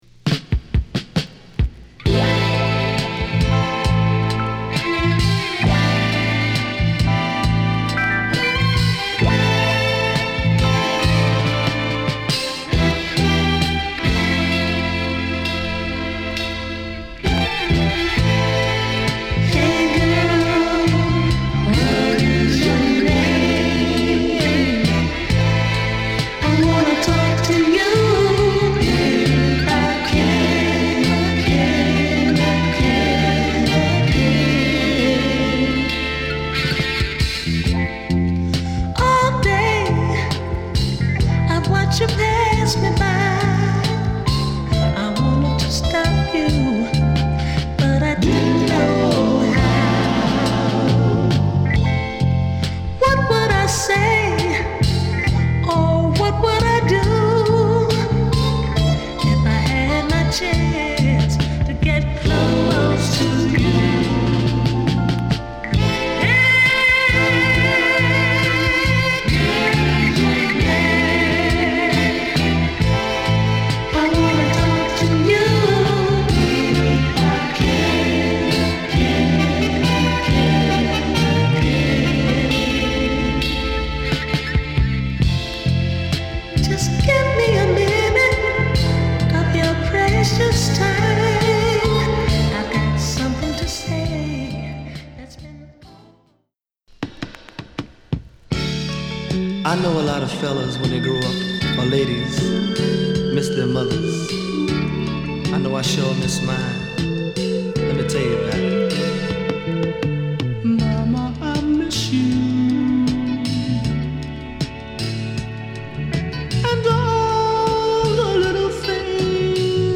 哀愁を帯びたイントロから外さないコーラスを聞かせる必殺の1曲！